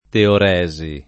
teoresi [ teor $@ i ]